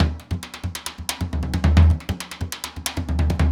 Surdo 1_Merengue 136-1.wav